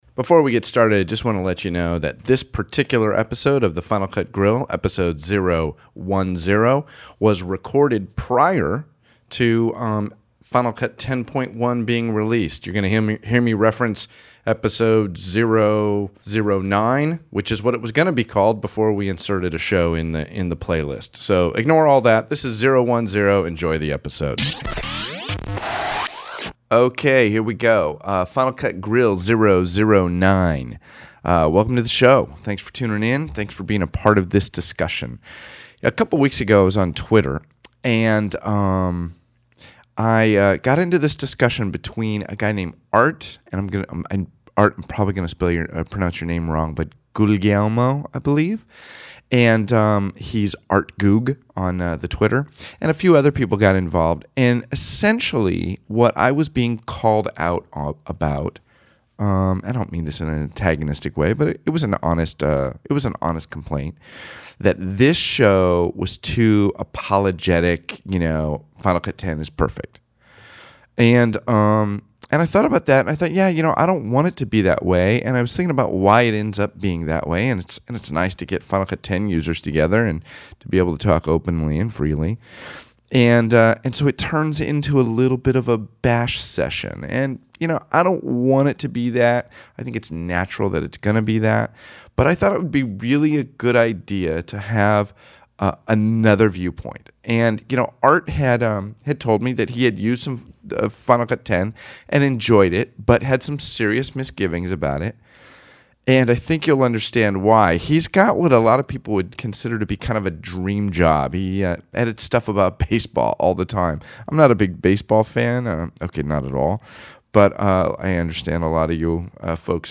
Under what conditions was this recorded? Within hours I had him on the Skyper and this was the discussion we had.